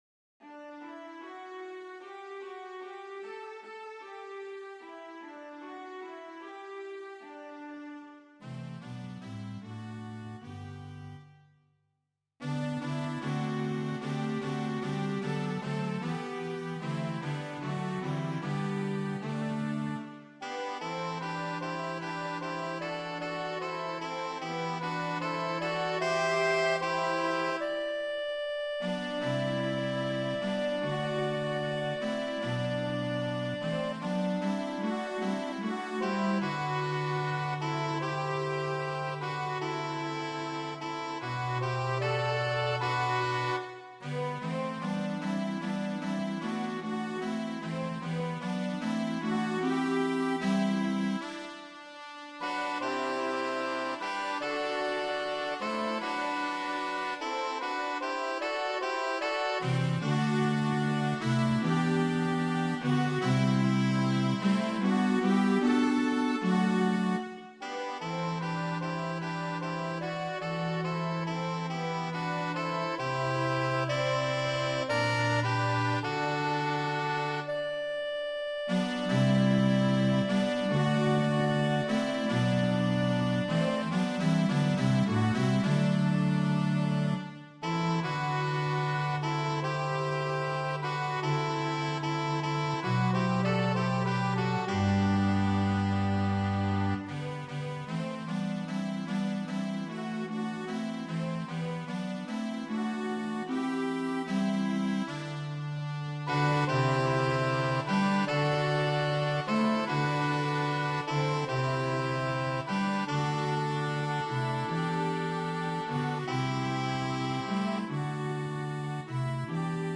Tonalité : libre